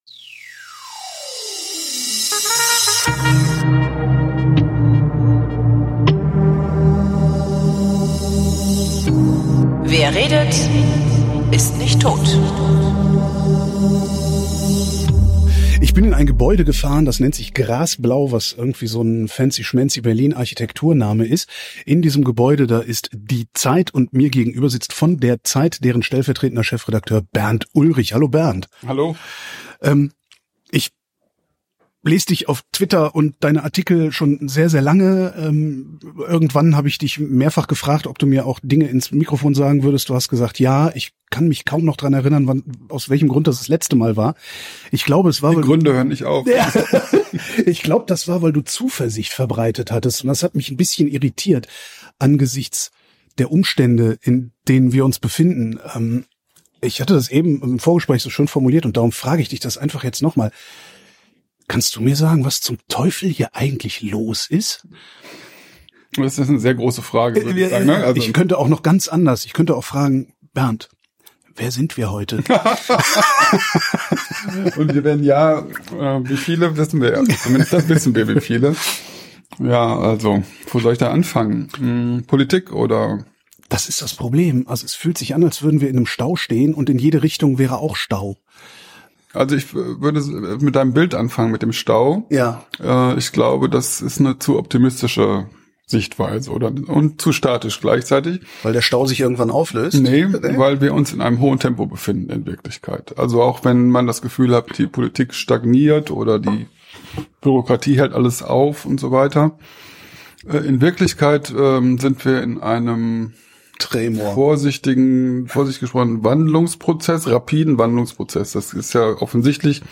Leider hatten wir viel zu wenig Zeit (und Bauarbeiten nebenan).